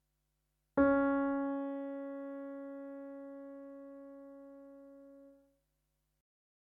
C#
Ex-1-Cm-tonic.mp3